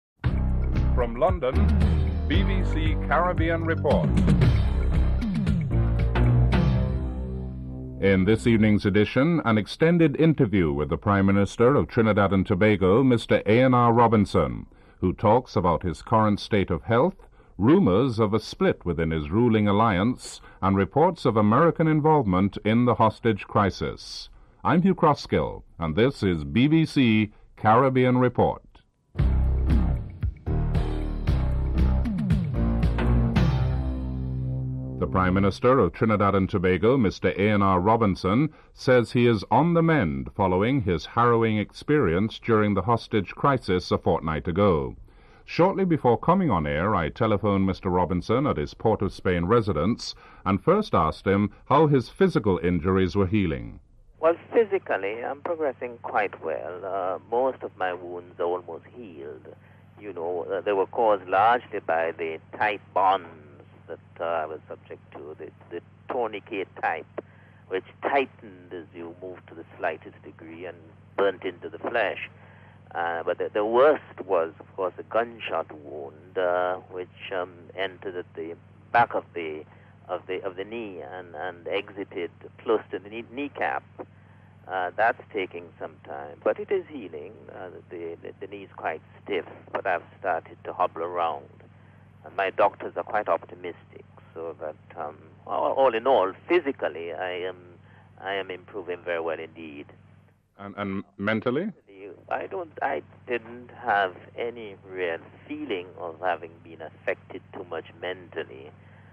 2. An extended interview with the Prime Minister of Trinidad and Tobago, A.N.R. Robinson, after the attempted coup by the Jamaat al Muslimeen.
3. Financial News (10:01-11:04)